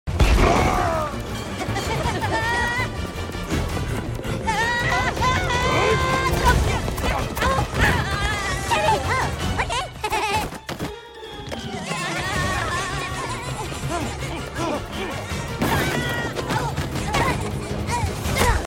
Minions funny sound effects free download